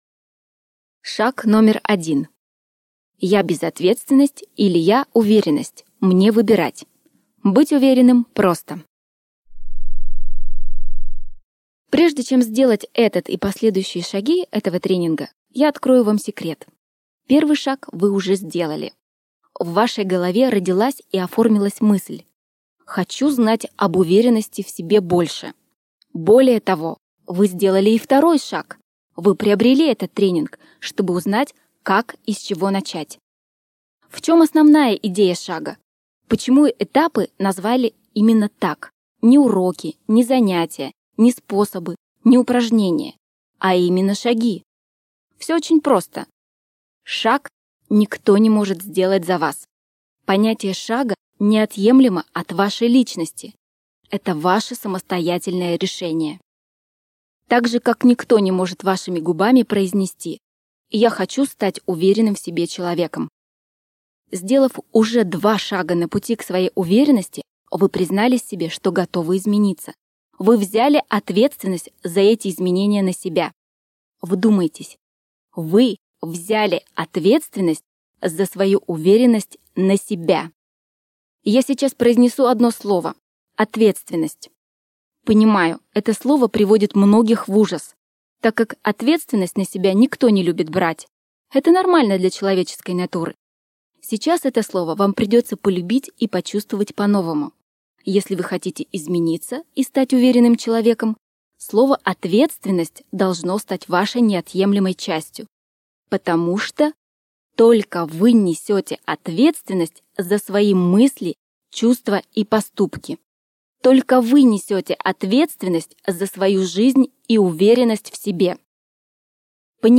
Аудиокнига Как выработать здоровый пофигизм или 12 шагов к уверенности в себе | Библиотека аудиокниг